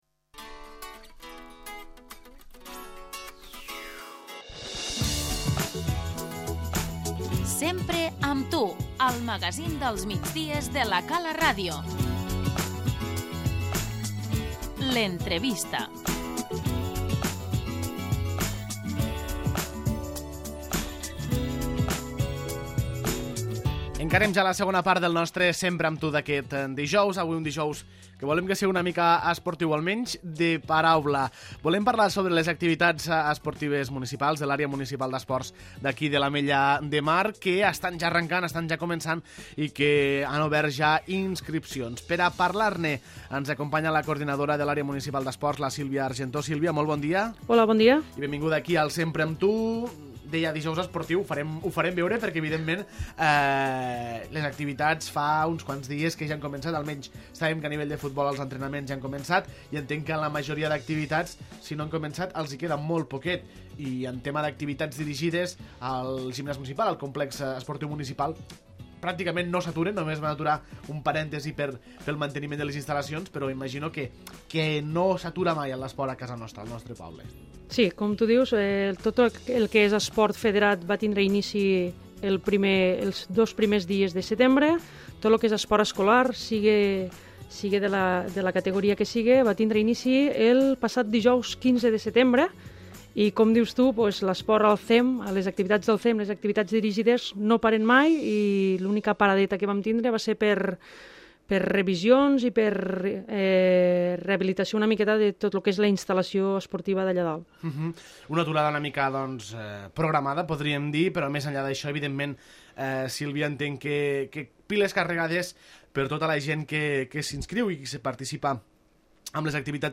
L'entrevista - Oferta esportiva tardor 2016